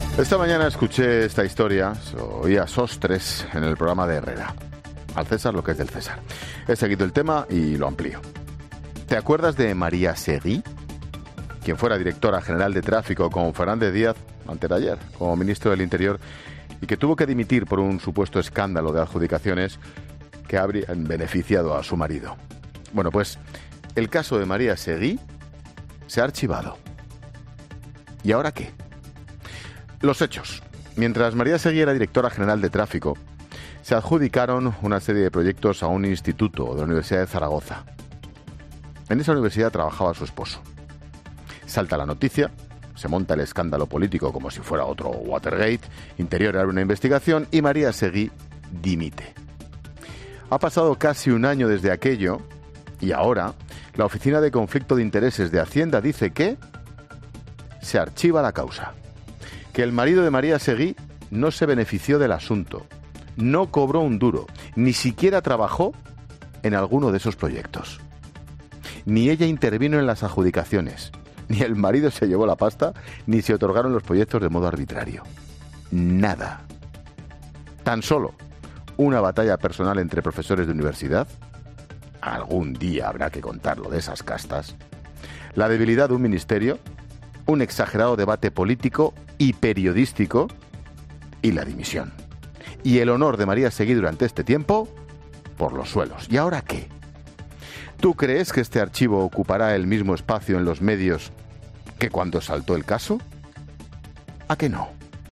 AUDIO: Monólogo 17h.